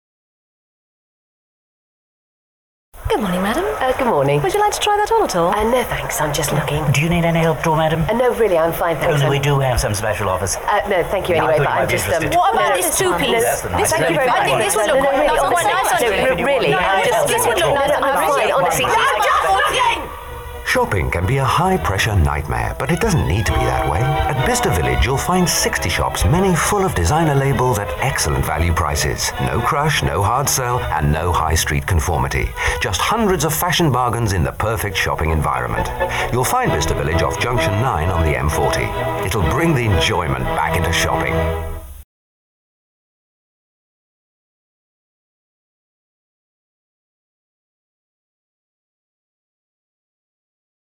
Bicester Village Radio Ad